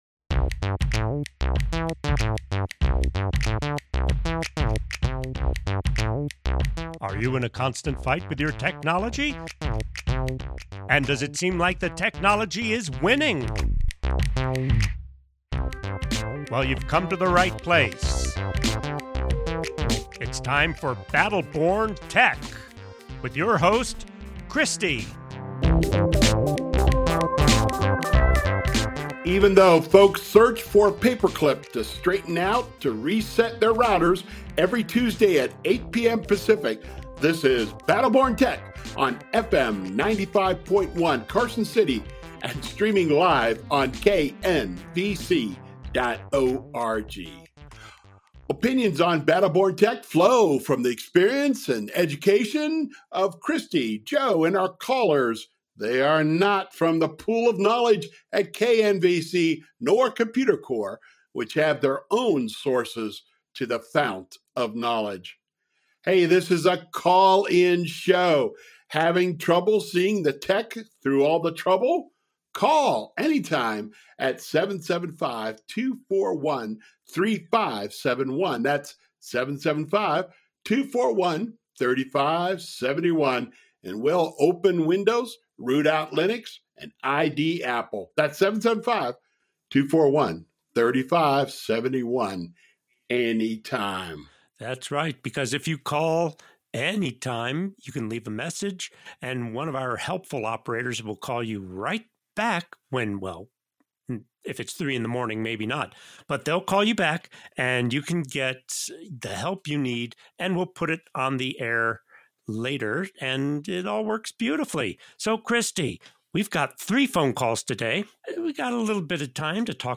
Has High Quality Stereo Recordings